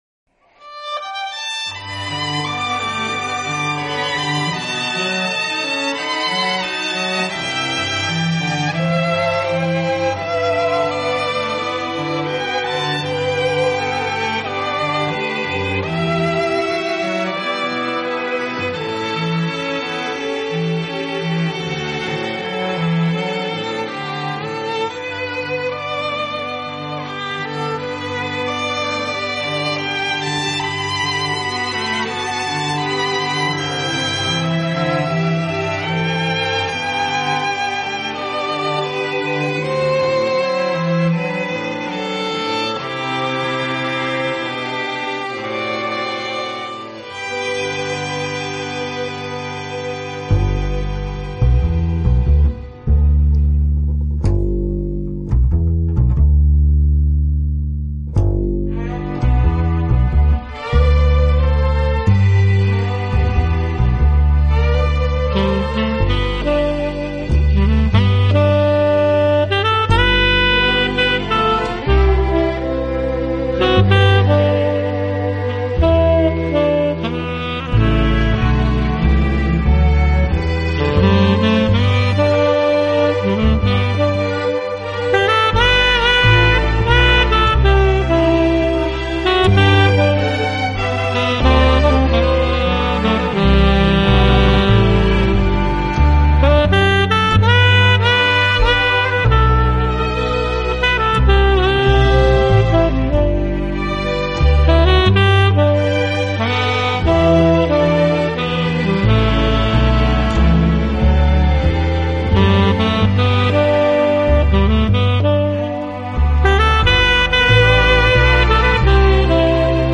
在乐曲当中担任主奏的萨克斯风，吹奏得相当浪漫迷人，绕梁三日。